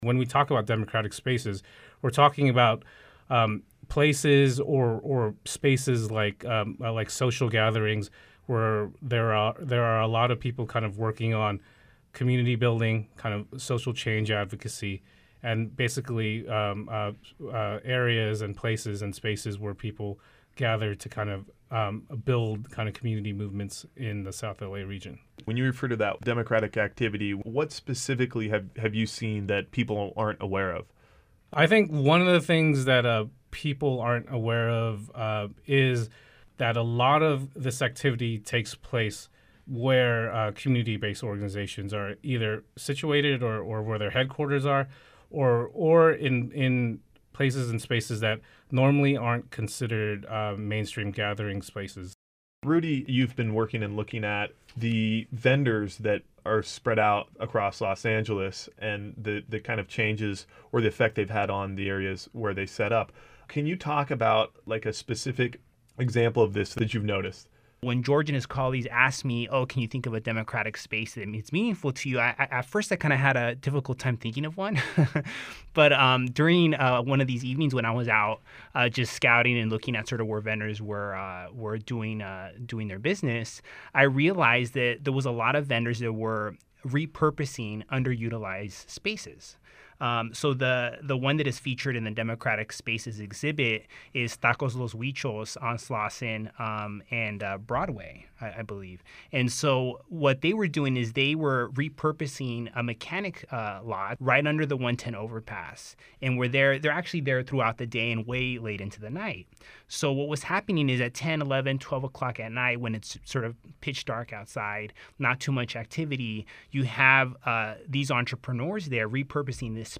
DemocraticSpaceInterview.mp3